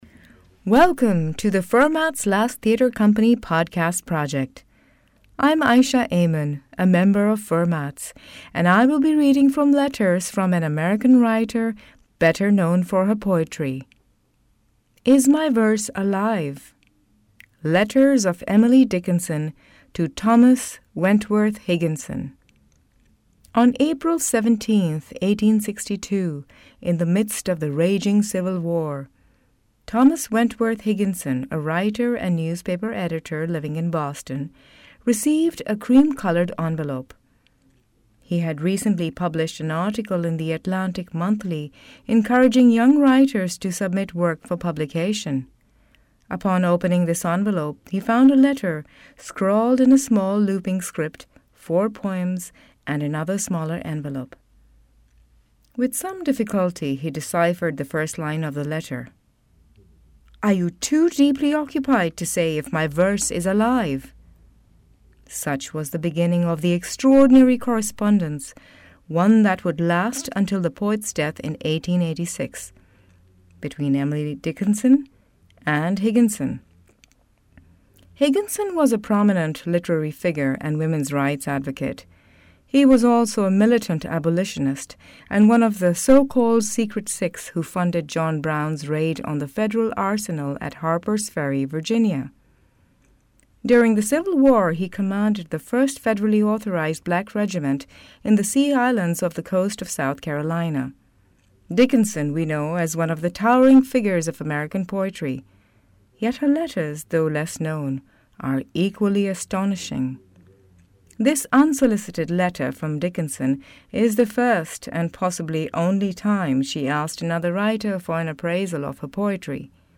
reads a selection of Dickinson’s letters to Thomas Wentworth Higginson, a prominent editor who in 1862 asked for young writers to submit works to The Atlantic Monthly.